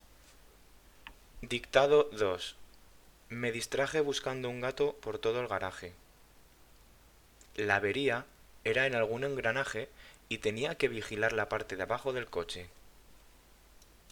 Dictado